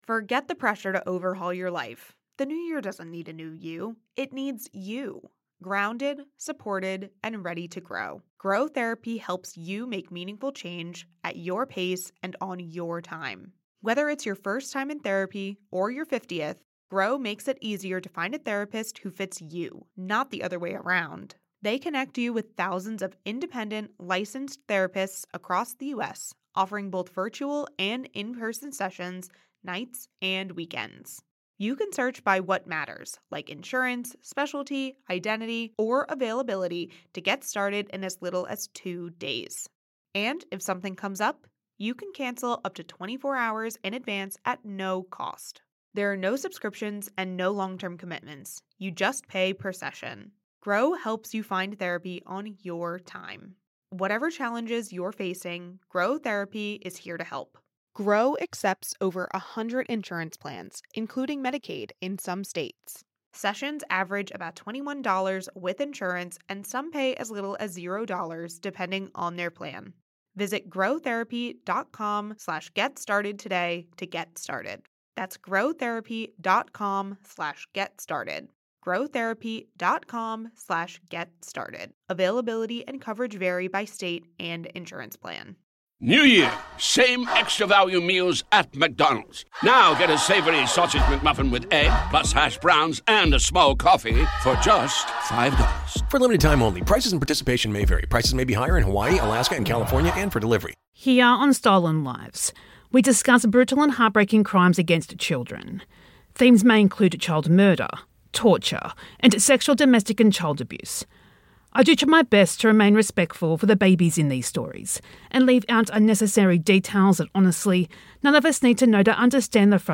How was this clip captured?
This is a re-release with sped up audio and gaps of silence removed.